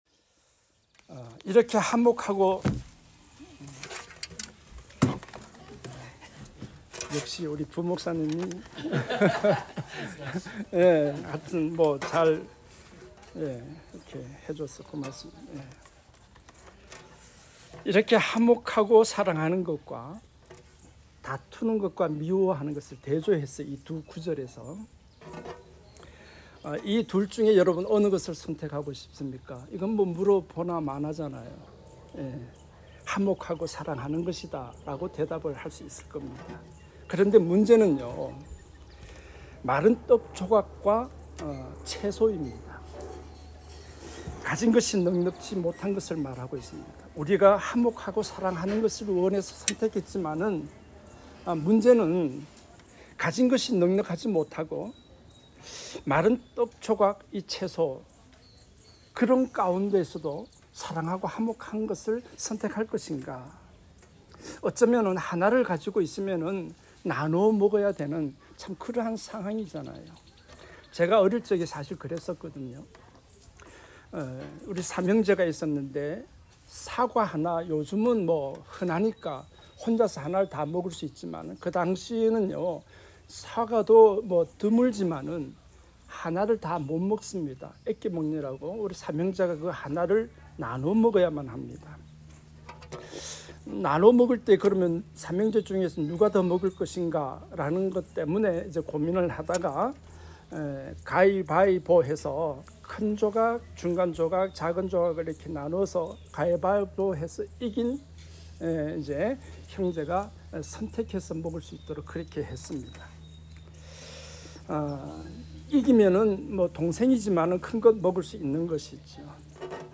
화목을 이루는 삶 ( 잠언17:1 ) 말씀